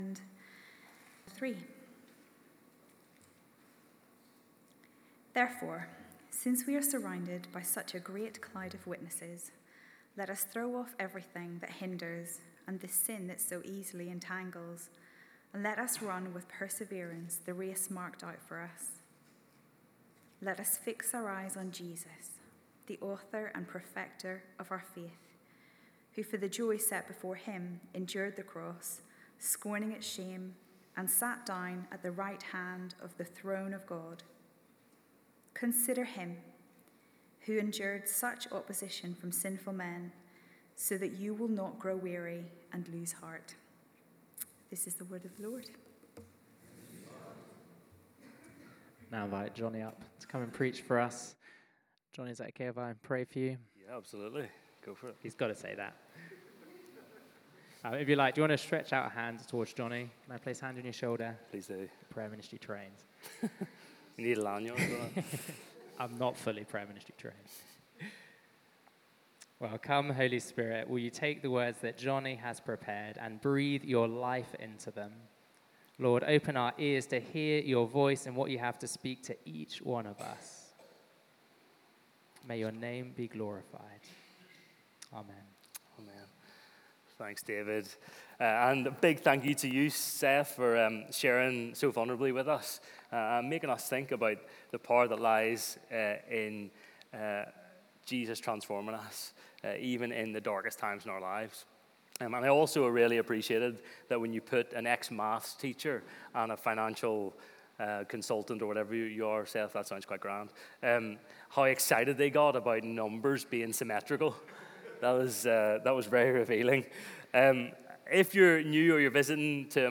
Emmanuel Church Sermons Genesis: Who Are We - Part 9 Play Episode Pause Episode Mute/Unmute Episode Rewind 10 Seconds 1x Fast Forward 30 seconds 00:00 / 31:08 Subscribe Share RSS Feed Share Link Embed